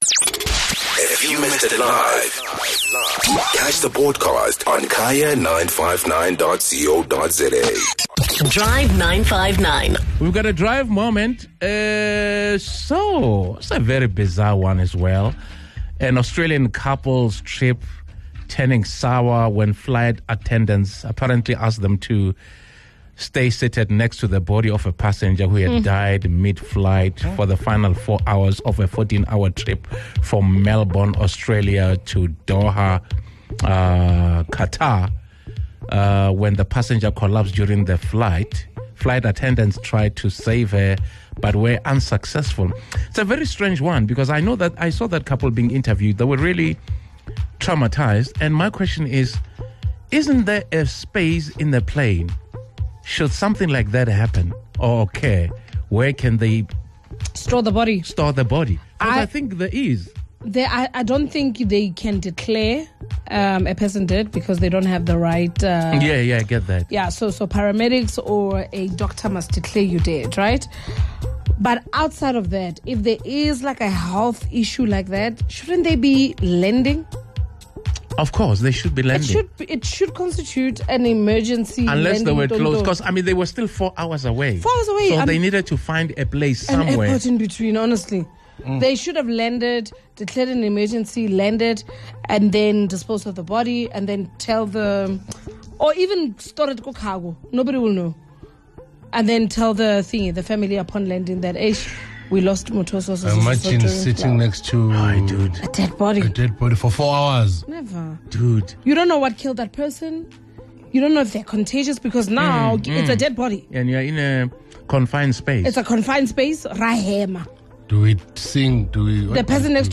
the Drive 959 team heard some strange and unbelievable encounters from today's listeners.